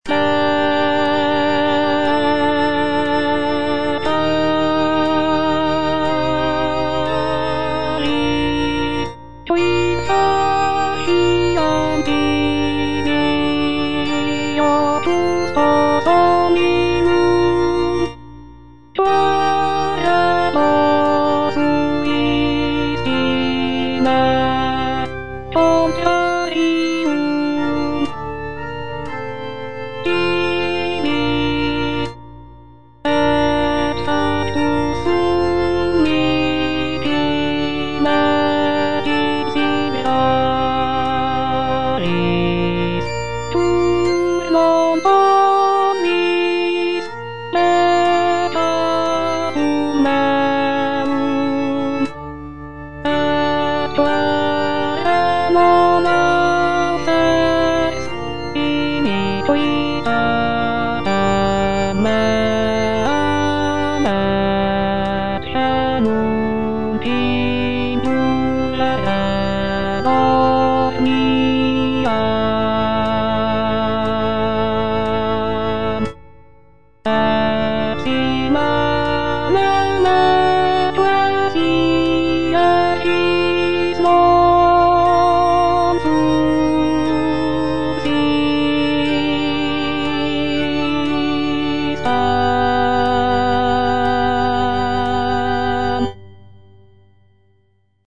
O. DE LASSUS - LECTIONES SACRAE NOVEM EX LIBRIS HIOB, LECTIO PRIMA LV676 Secunda pars: Peccavi quid faciam tibi - Alto (Voice with metronome) Ads stop:
The work is characterized by its rich harmonies, expressive melodies, and dramatic contrasts, creating a powerful and moving musical interpretation of the scripture.